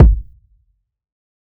TC Kick 01.wav